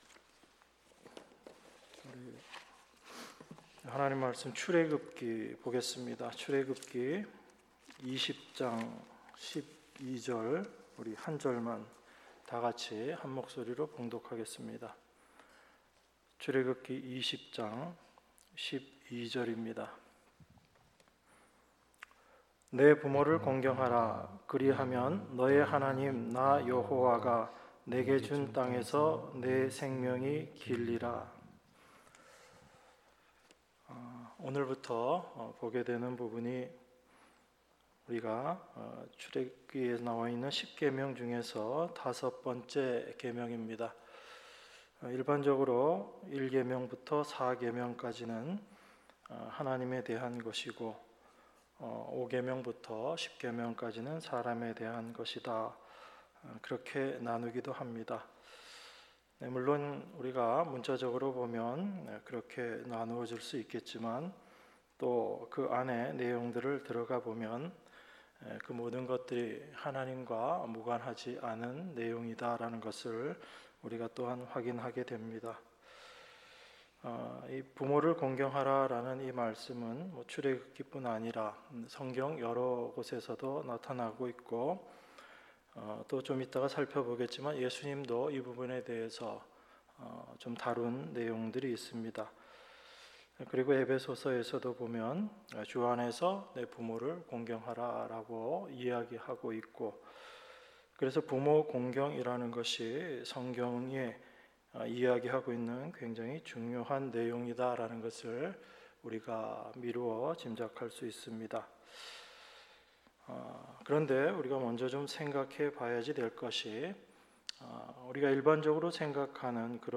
수요예배 출애굽기 20장 12절